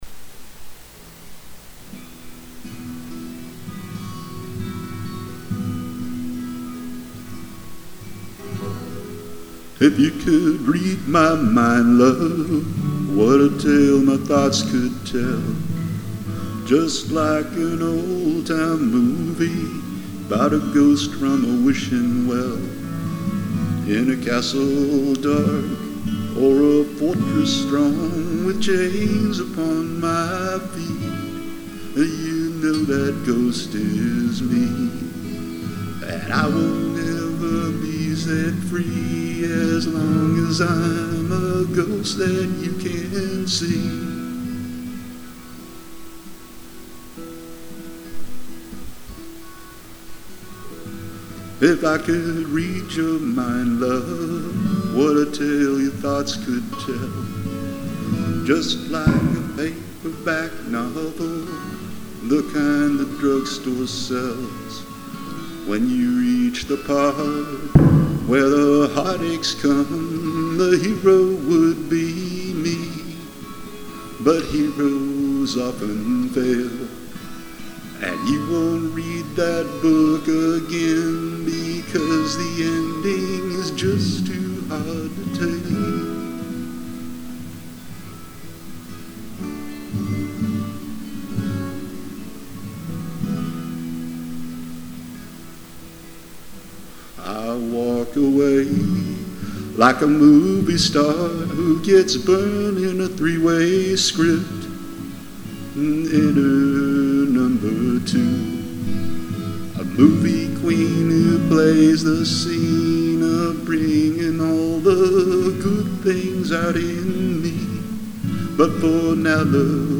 Vocals, 12 String Guitar